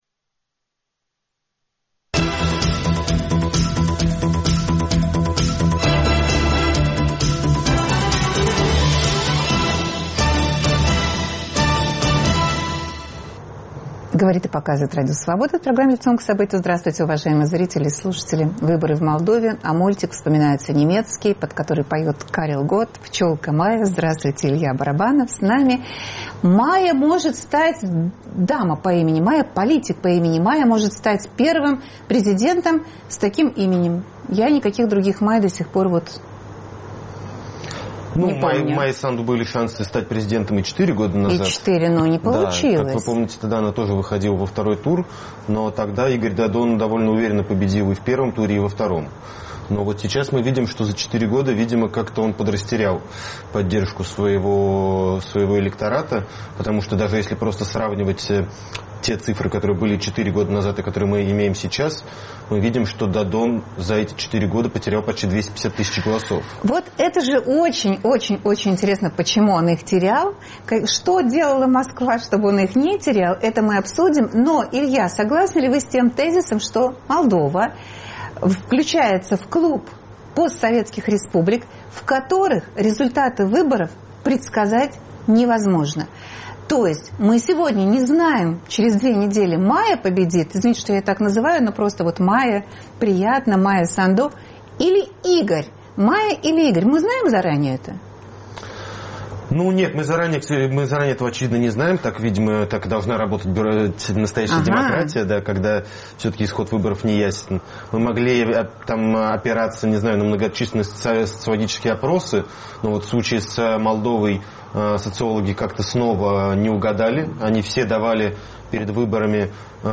Обсуждают политический аналитик